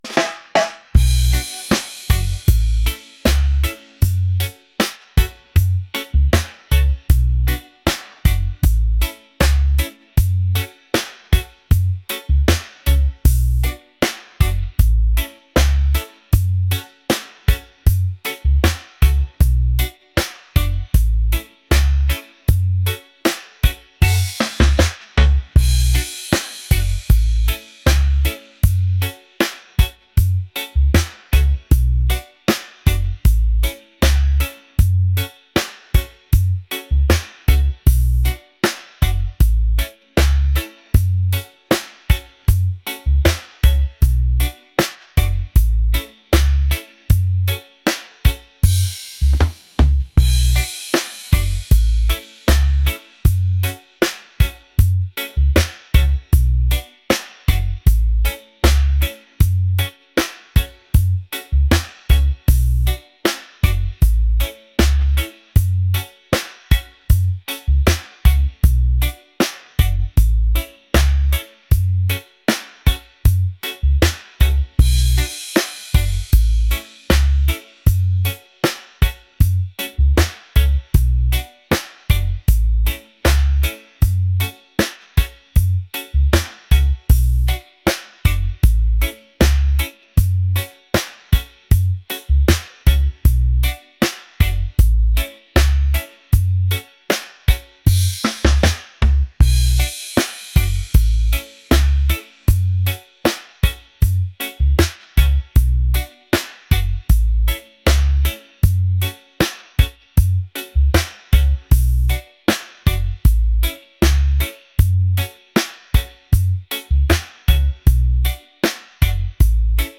laid-back | soulful | reggae